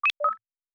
Digital Click 10.wav